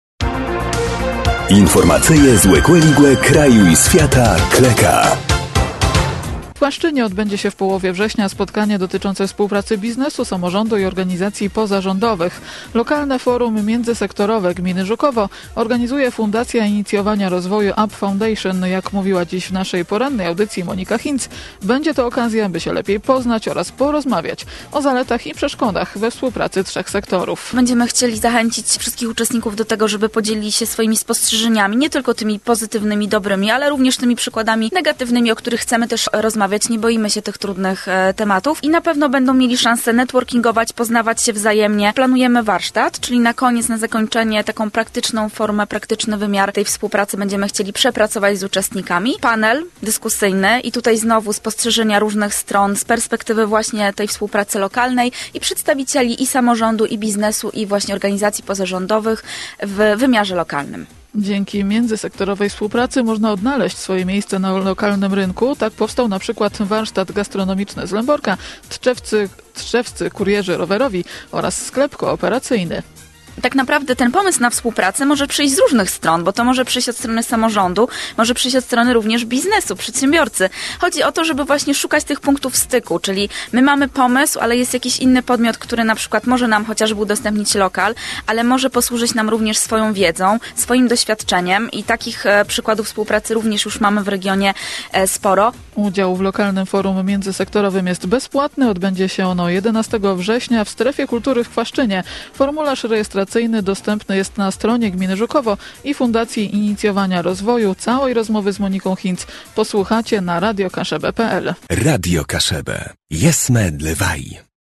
rozmowa.mp3